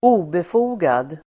obefogad adjektiv, unjustified Uttal: [²'o:befo:gad] Böjningar: obefogat, obefogade Synonymer: grundlös Definition: som det inte finns skäl till (unwarranted, unfounded) unwarranted adjektiv, obefogad , oberättigad , otillåten , omotiverad